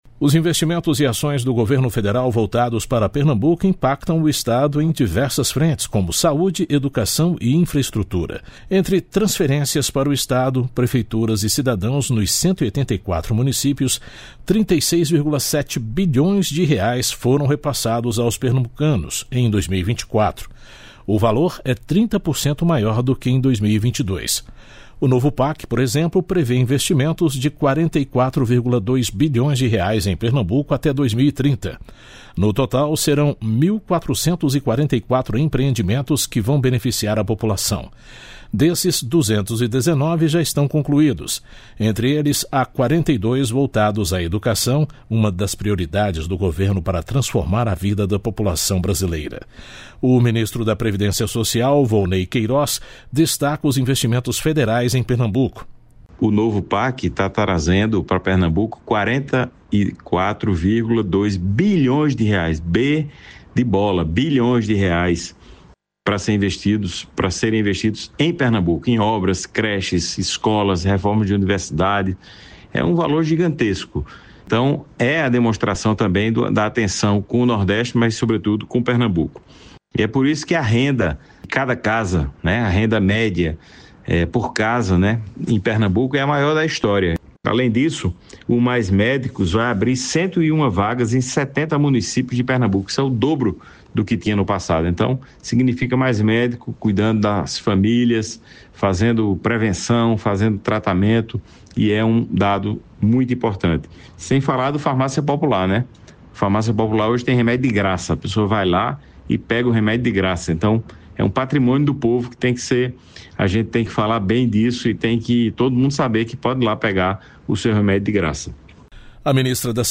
Os ministros da Previdência Social, Wolney Queiroz, e da Ciência, Tecnologia e Inovação, Luciana Santos, destacam os investimentos federais em Pernambuco.